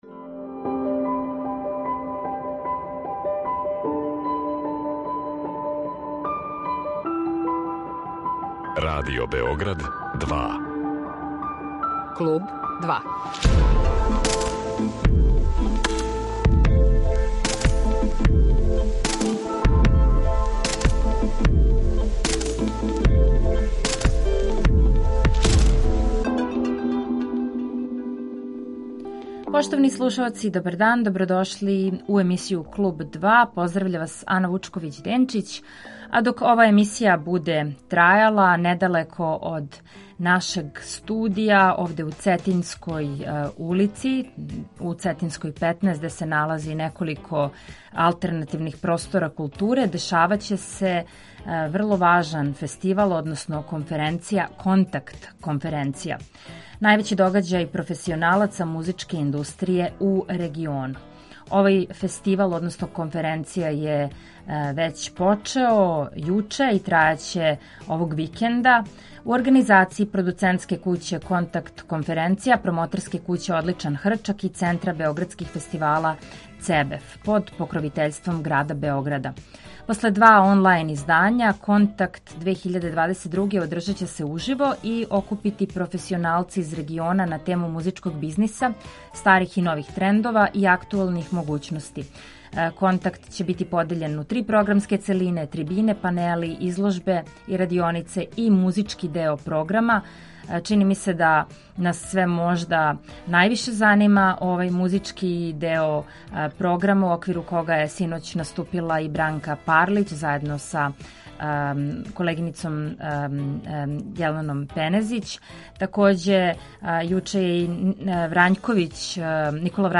Слушаћемо и музику извођача који наступају на Контакту.